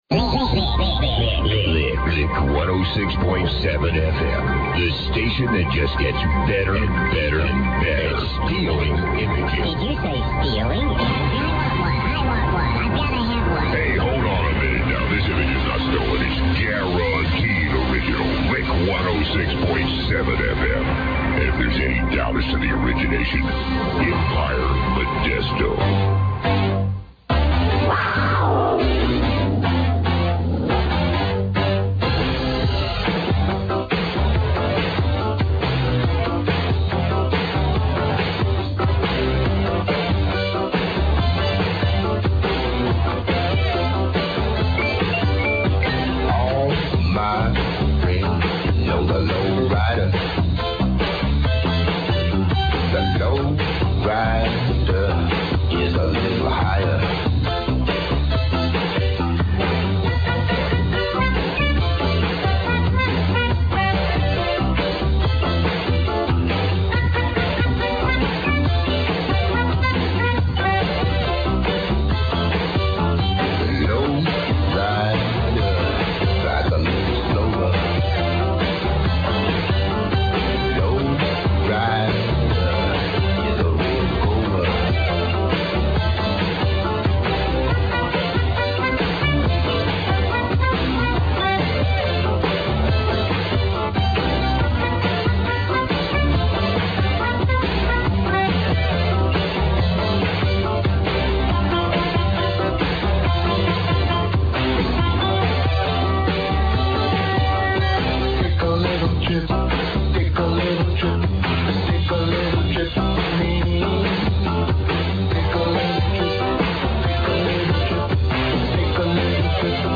This station sounded like it was using pro equipment and had very PRO DJ and production team , liners , sound effects and great pop music that was not getting airplay on the crappy main stream station that are all under corporate control.
here is one recording that was saved by the local indy news crew made from a radio receiver 'over the air' using a beam antenna over about a 14 mile path. Lick 106.7 fm aircheck mp3 ( CLICK HERE TO PLAY THE MP3 )